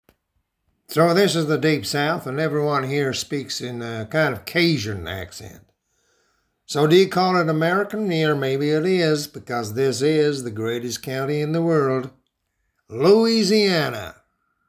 Gravitas, Solid, Deep